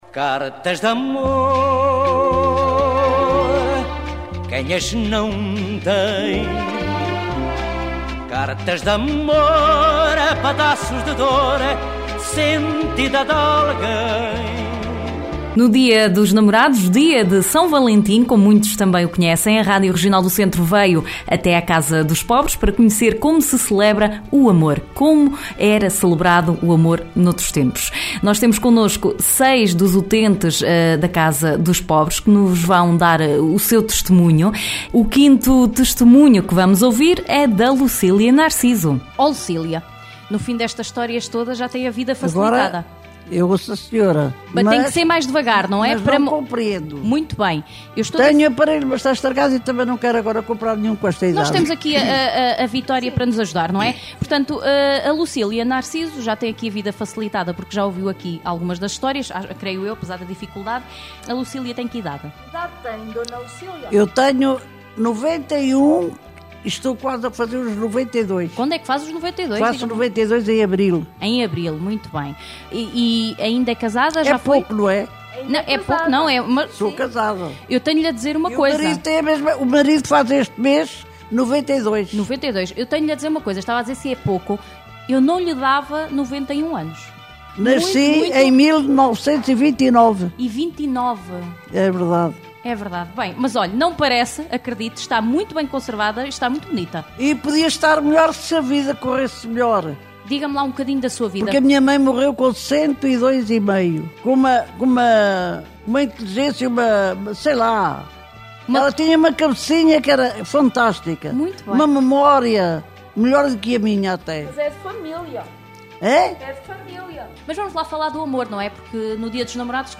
A Regional do Centro foi à Casa dos Pobres, em Coimbra, para saber junto de alguns utentes como se celebrava o amor há algumas décadas atrás. Oiça aqui um dos testemunhos.